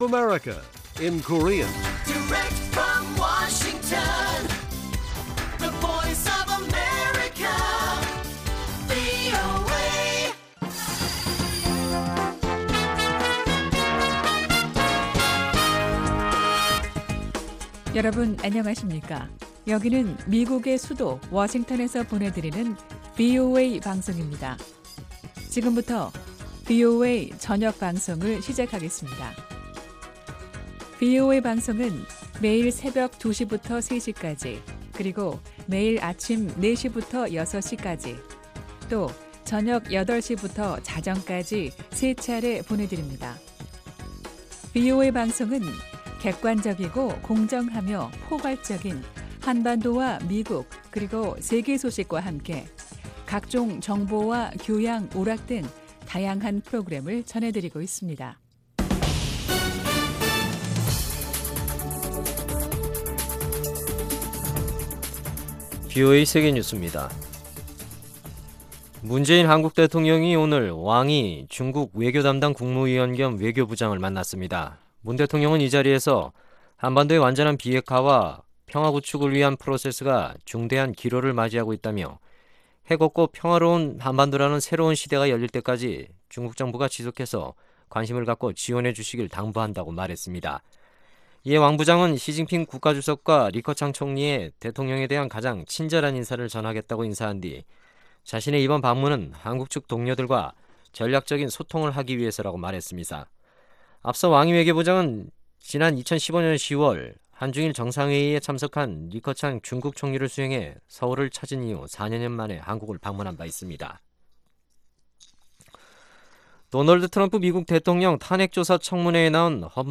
VOA 한국어 간판 뉴스 프로그램 '뉴스 투데이', 2019년 12월 5일 1부 방송입니다. 미국은 단 한번도 북한에 대한 군사력 사용 방안을 의제에서 내려놓은 적이 없다고 미 국방부 고위 관리가 밝혔습니다. 도널드 트럼프 대통령의 무력 사용 언급에 대해 북한은 “무력사용은 미국 만이 갖고 있는 특권이 아니”라며 맞대응 했습니다.